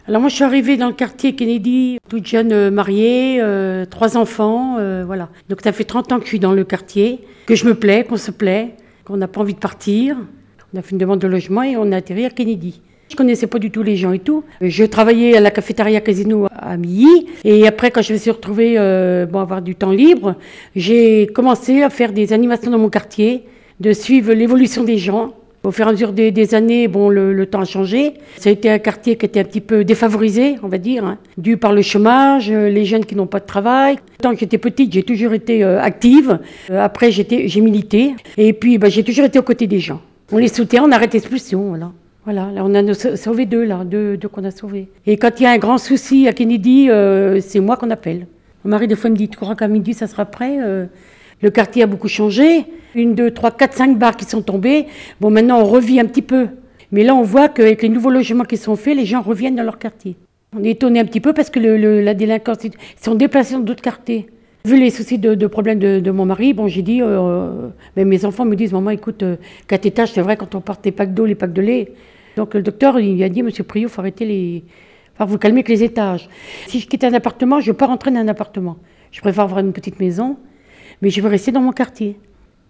Un enregistrement audio de parole: